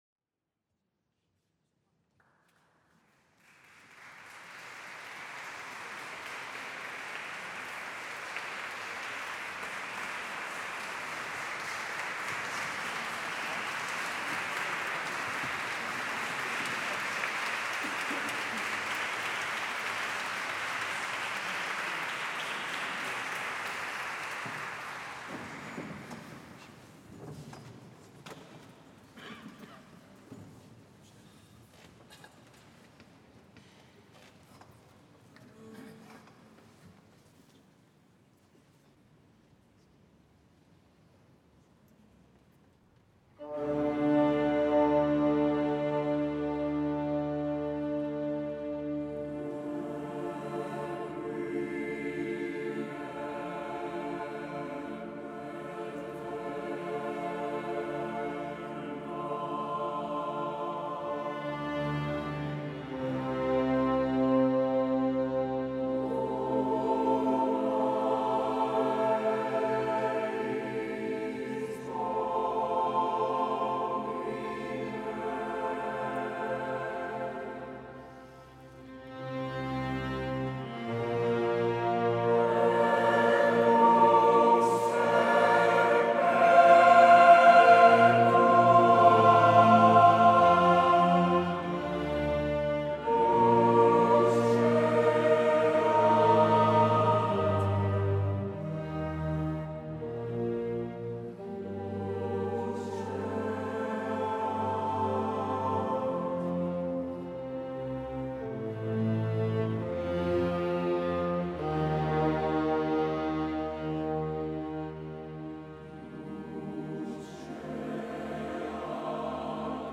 Εθνική Συμφωνική Ορχήστρα της ΕΡΤ
Χορωδίες Ε.Μ.Π. & Παντείου Πανεπιστημίου, Ορφείου Ωδείου και Filii Notas
26/10/2023 Ιερός Καθολικός Καθεδρικός Ναός Αγ. Διονυσίου Αεροπαγίτη